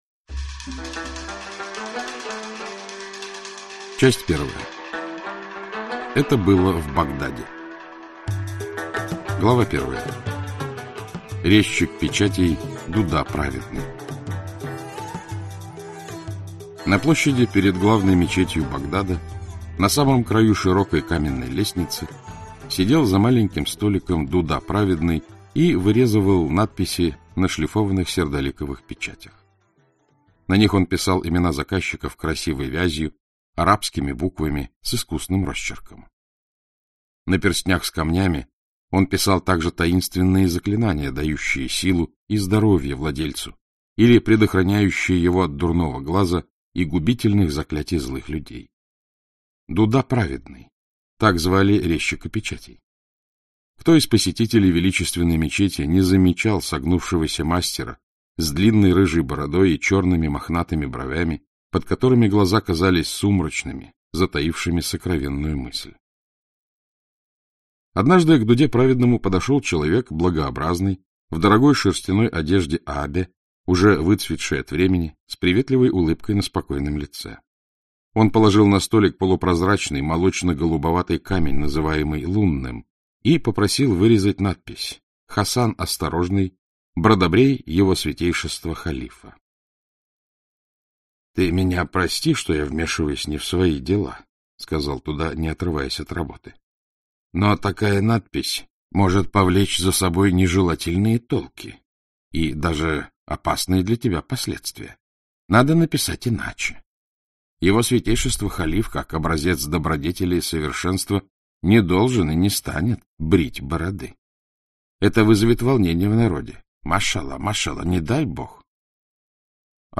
К последнему морю. Ян В. Аудиокнига. читает Александр Клюквин К последнему морю. Ян В. Аудиокнига. читает Александр Клюквин Продолжительность: 28:52